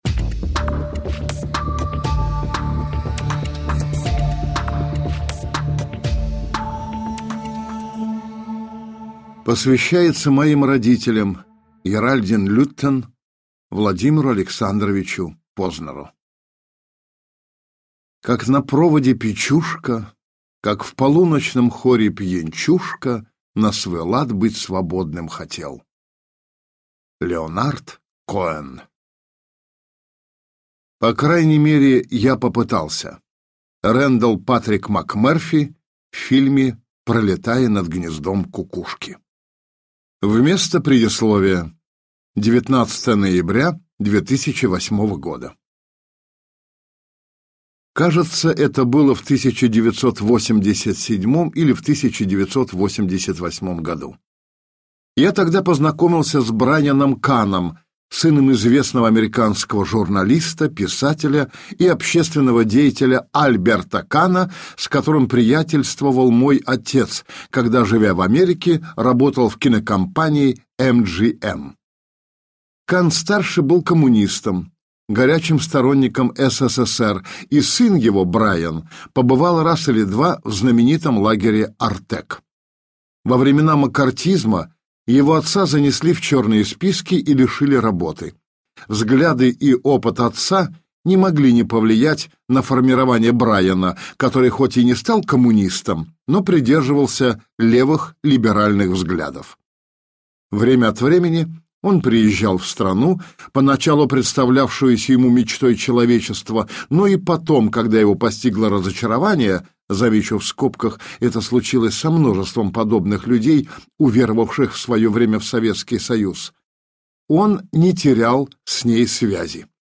Аудиокнига Прощание с иллюзиями - купить, скачать и слушать онлайн | КнигоПоиск